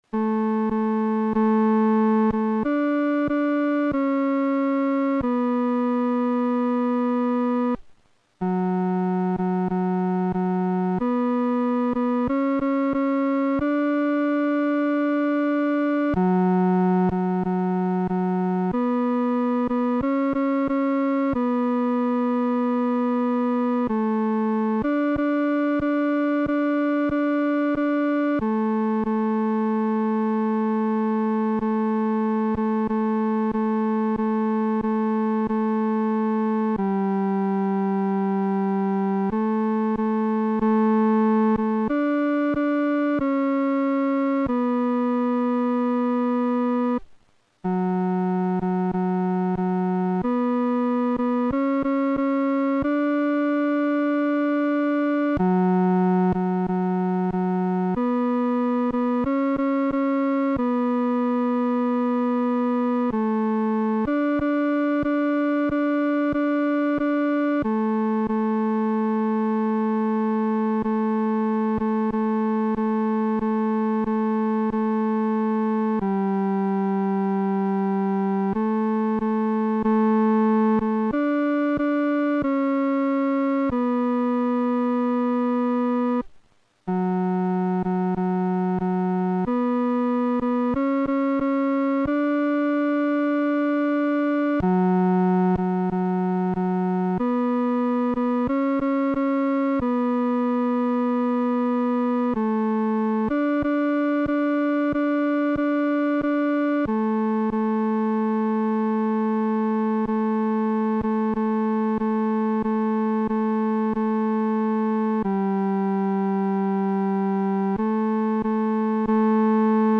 伴奏
男高
这首诗歌宜用不快的中速来弹唱，声音要饱满。